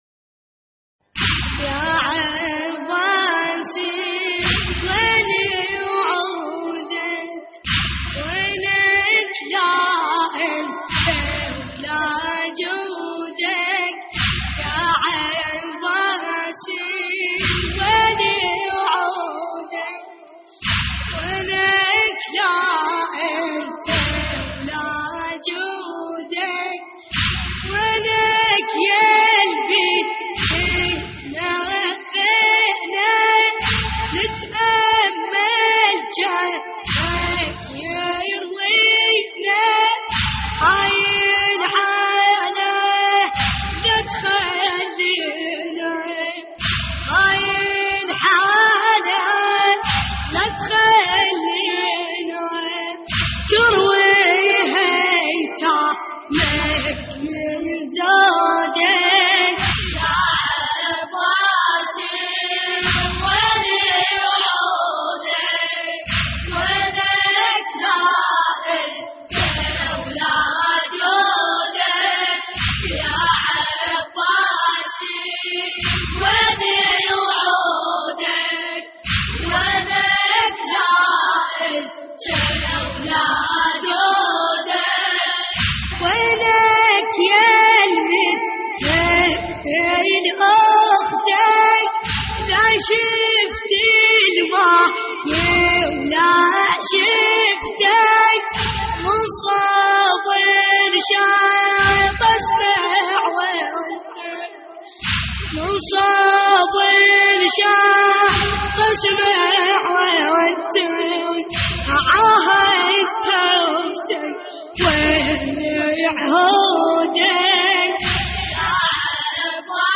استديو